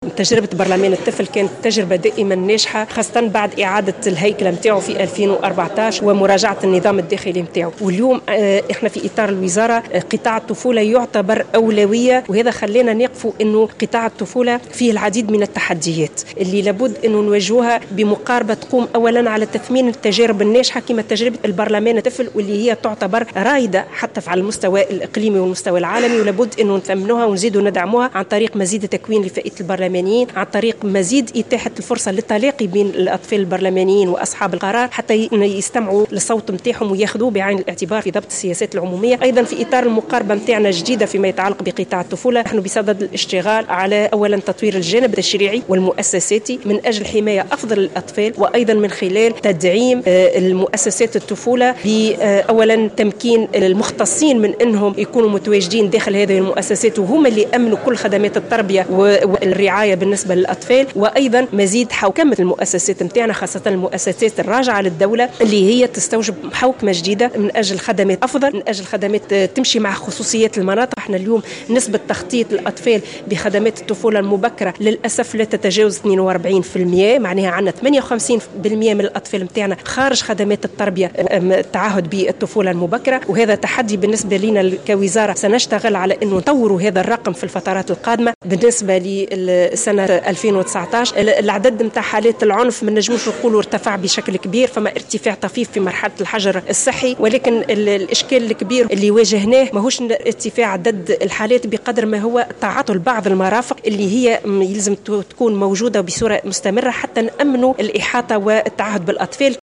وأضافت في تصريح اليوم لمراسلة "الجوهرة أف ام" على هامش جلسة عامة للدورة العادية الأولى لبرلمان الطفل، أن الوزارة بصدد الاشتغال على تطوير الجانب التشريعي والمؤسساتي من أجل حماية أفضل للأطفال.